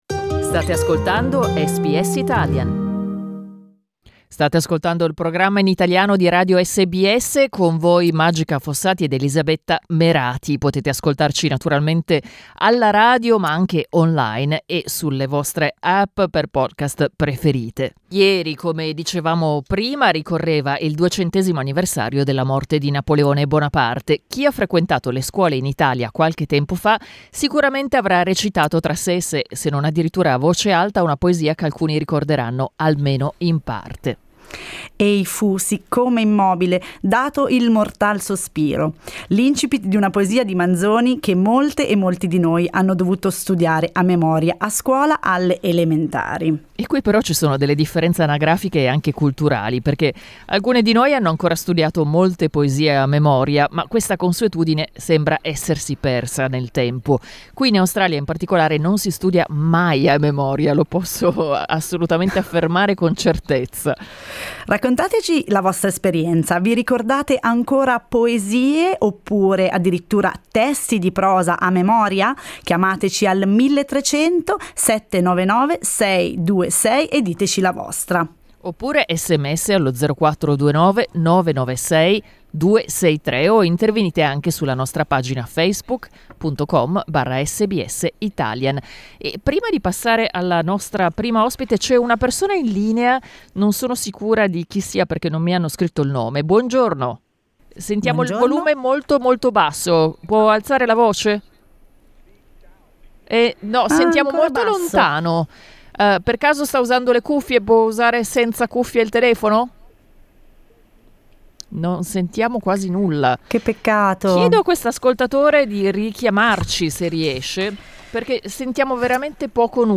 Ascolta il dibattito: LISTEN TO L'arte di memorizzare va perdendosi?